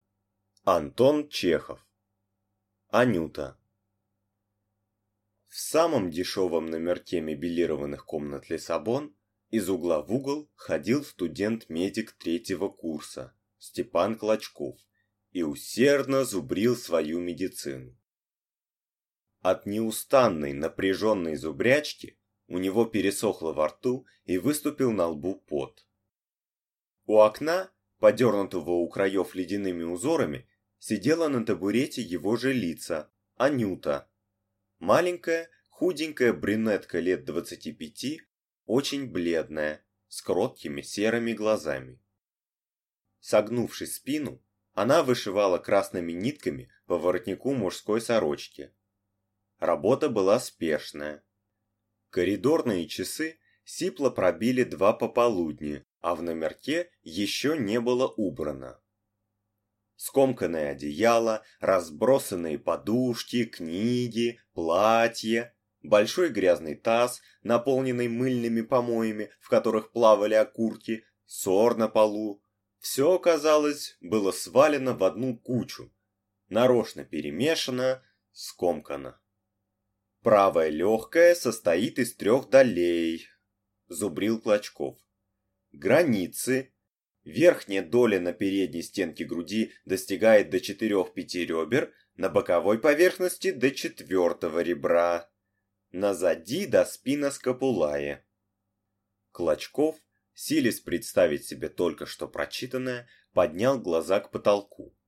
Аудиокнига Анюта | Библиотека аудиокниг